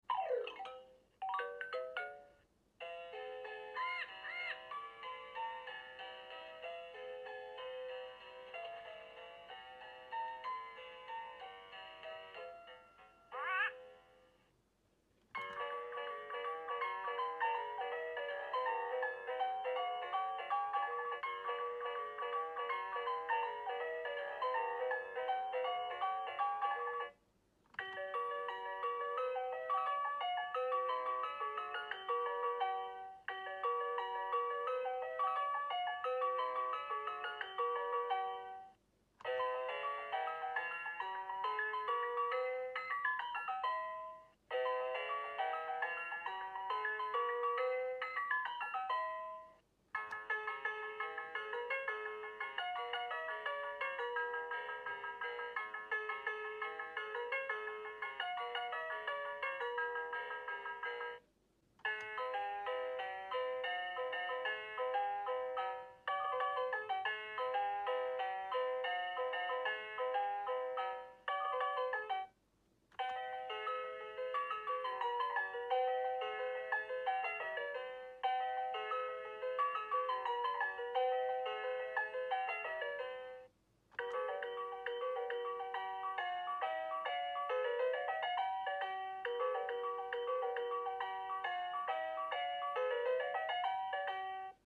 Play & Learn Toucan Piano sound effects free download